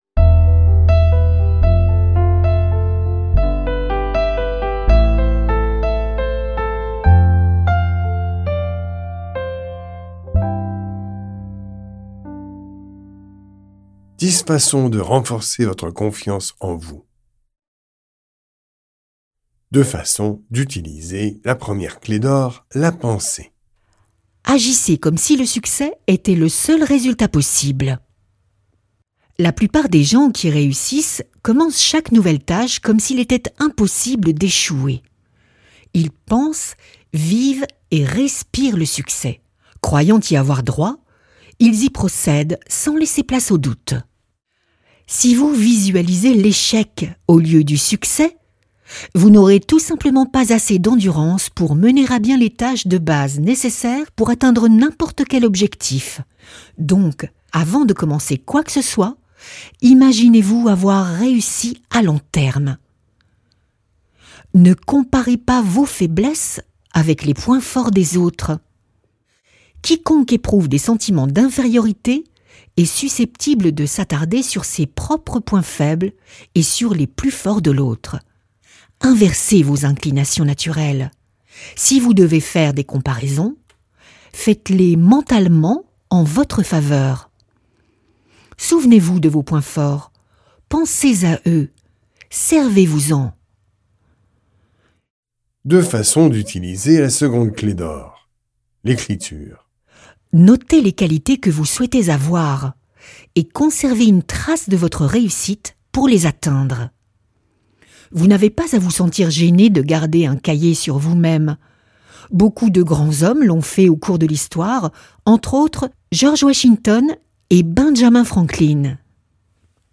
Ce livre audio, unique, vous emmènera dans un voyage pour vous aider à comprendre comment votre propre pensée mal guidée mine votre bonheur.